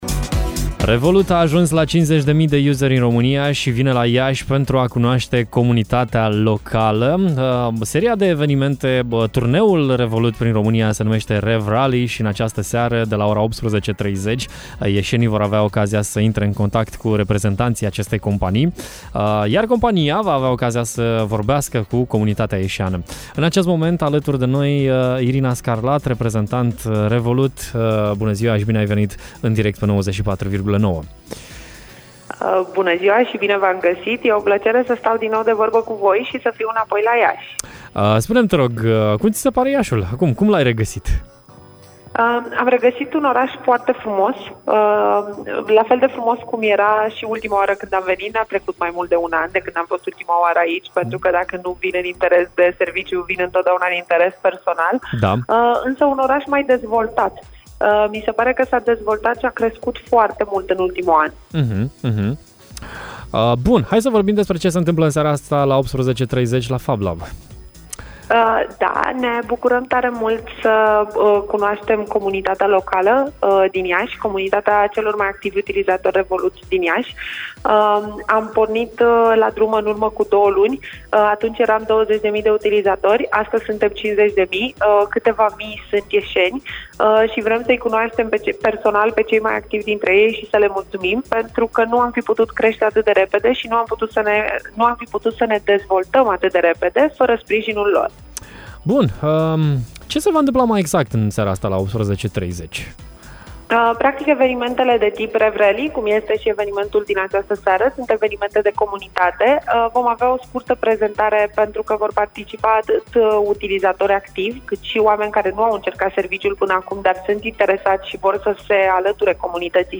Mai multe detalii despre Revolut dar și despre evenimentul din această seară am aflat în direct la Radio Hit